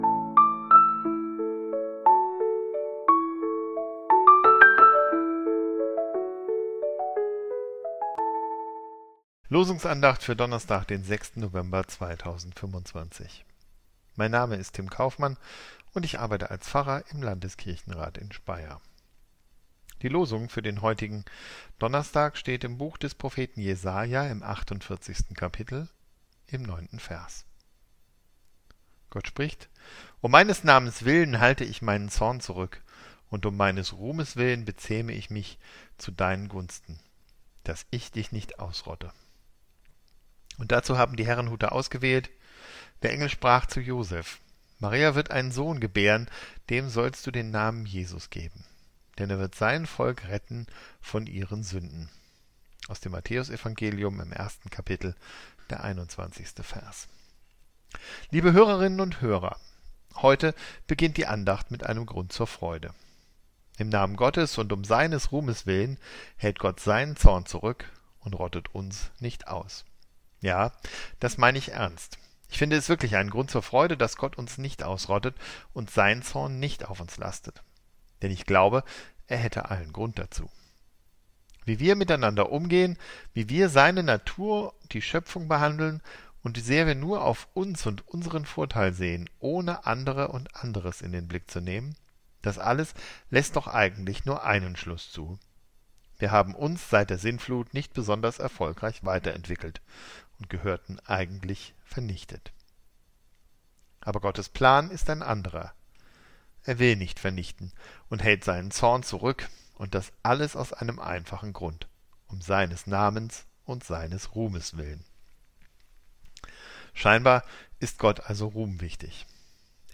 Losungsandacht für Donnerstag, 06.11.2025
Losungsandachten